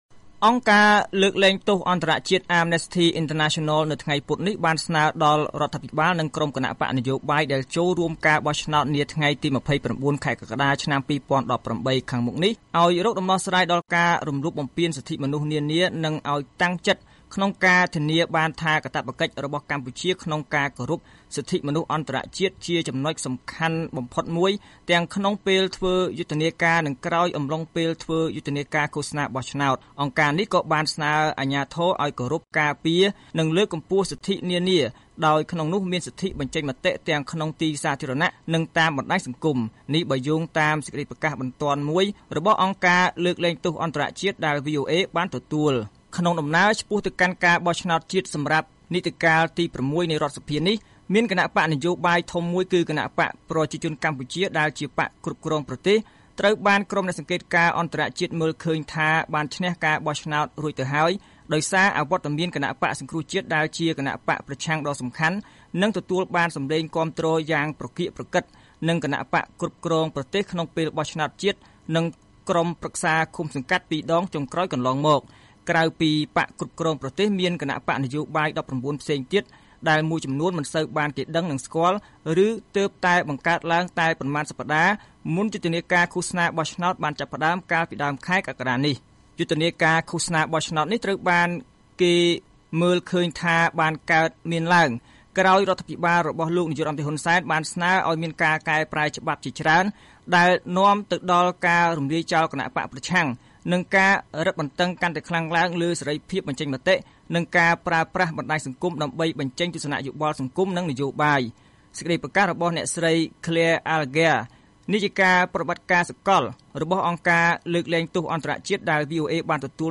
ស្តាប់សេចក្តីរាយការណ៍៖ អង្គការ Amnesty International អំពាវនាវឲ្យរដ្ឋាភិបាលនិងគណបក្សចូលរួមការបោះឆ្នោត ដោះស្រាយការរំលោភសិទ្ធិមនុស្ស